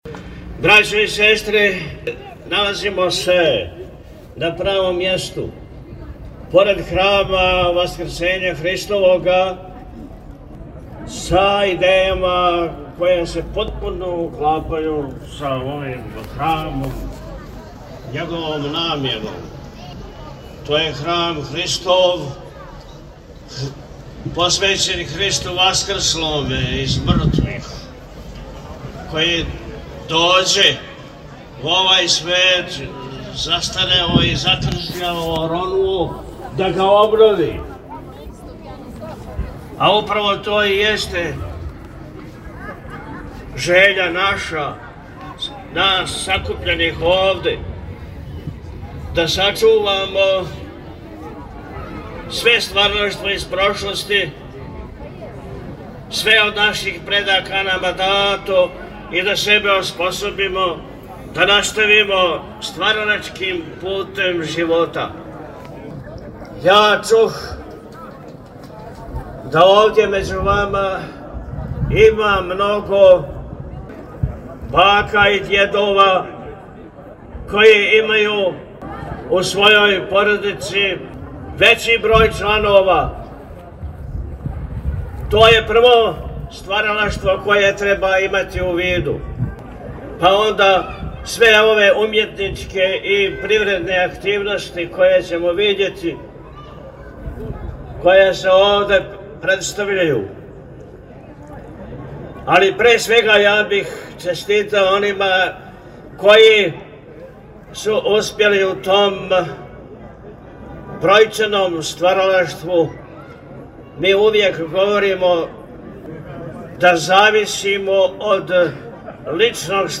У четвртак 18. септембра 2025. године у порти храма Васкрсења Христовог у Новом Прибоју одржана је манифестација Тромеђа без међа, у организацији Удружења жена Сачувајмо село из Прибоја.